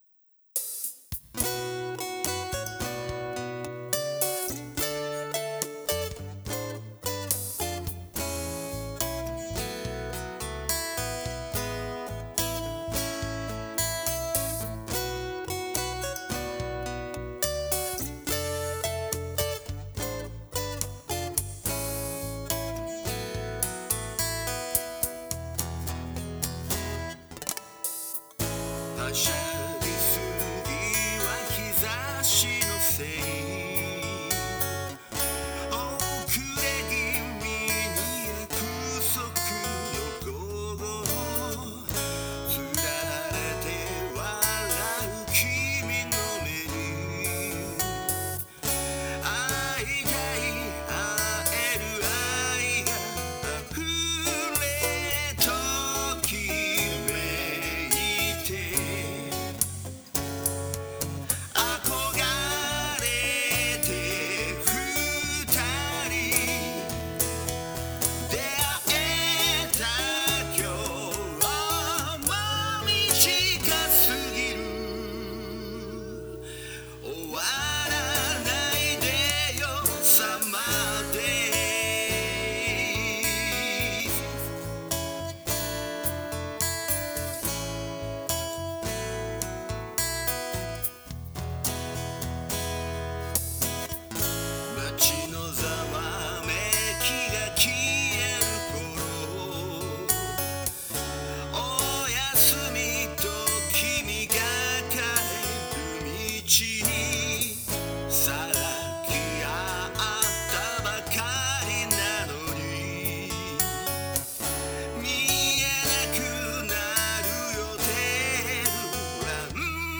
映像を加えてエンコードすると音が悪くなるので・・・。